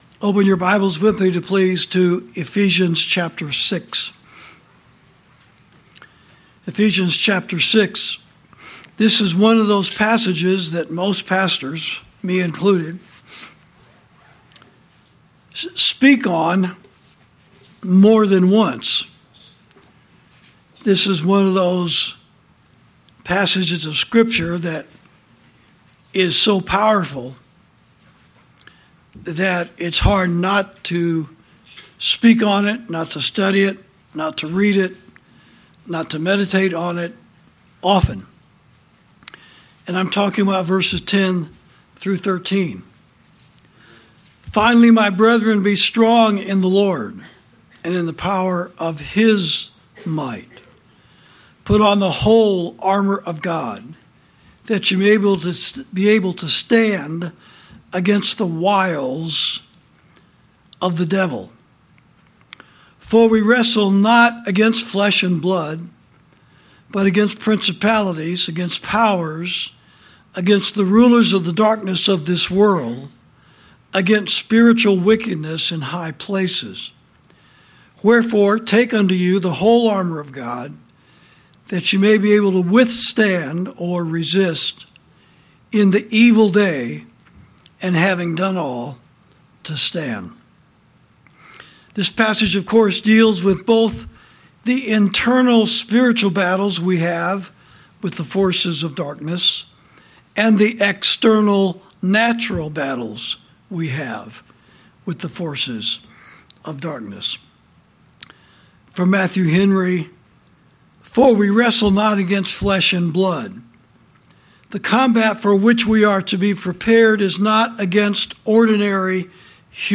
Sermons > Wickedness In High Places